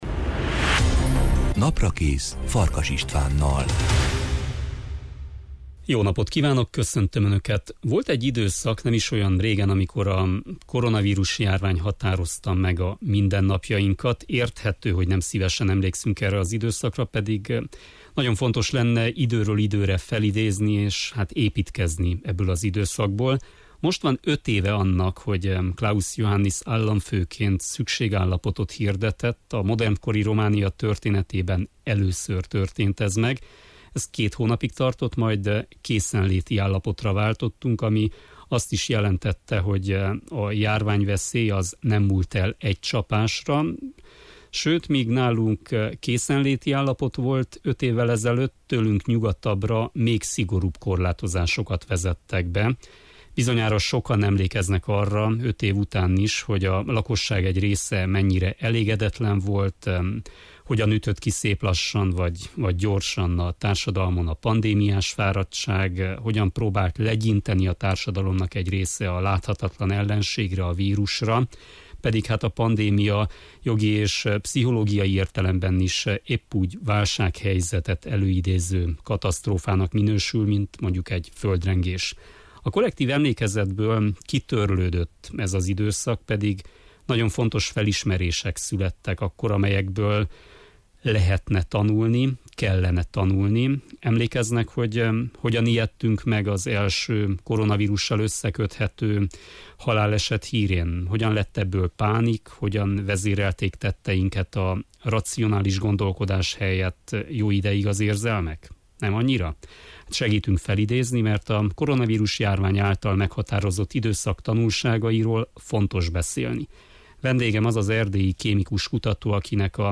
a Naprakész vendége.